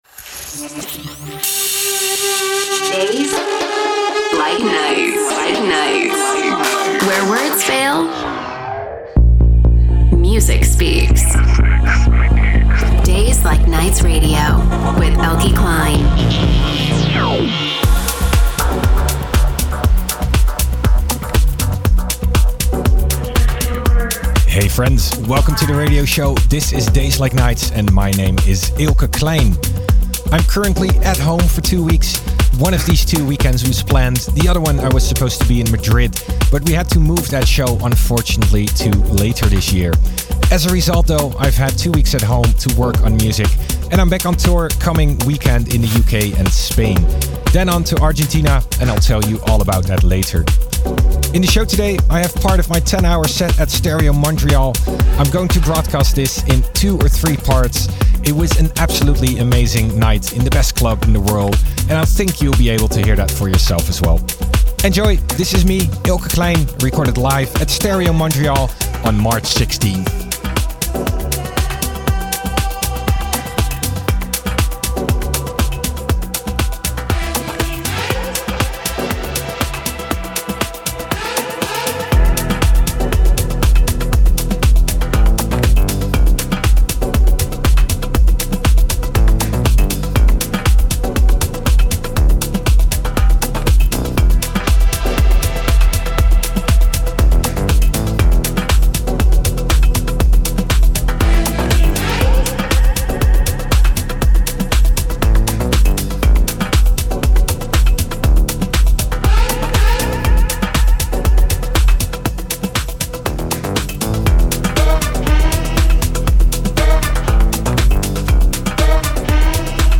DJ Mix & Live Set